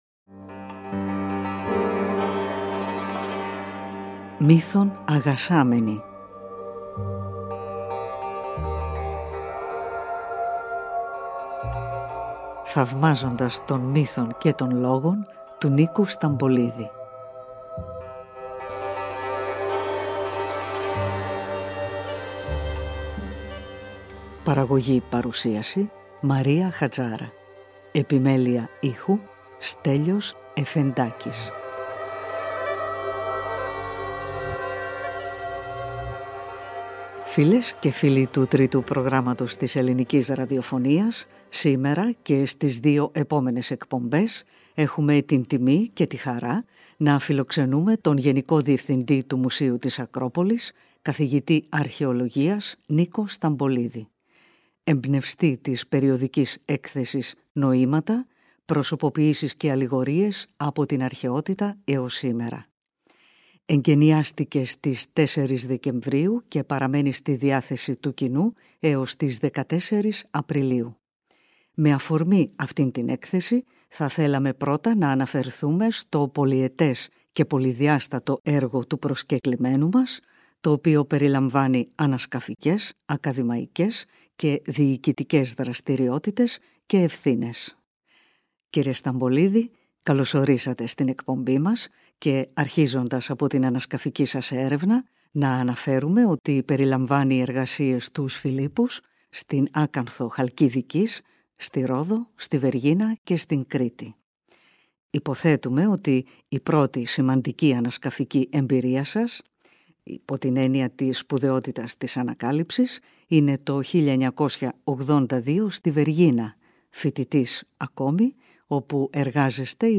Στο 1ο μέρος, (στην εκπομπή του Σαββάτου 9 Μαρτίου), ο Νίκος Σταμπολίδης μιλά στο Τρίτο Πρόγραμμα και στην εκπομπή για το πολυετές και πολυδιάστατο έργο του, το οποίο περιλαμβάνει ανασκαφικές, ακαδημαϊκές και διοικητικές δραστηριότητες.
Συνεντεύξεις